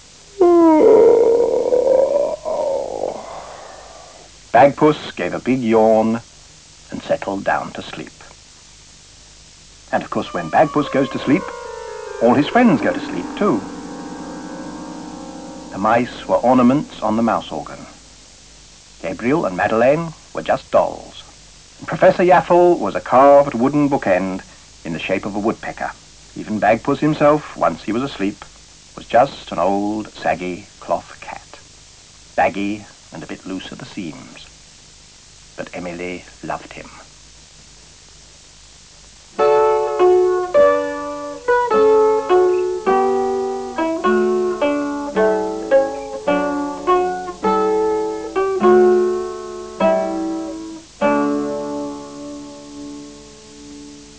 The end theme music and narration.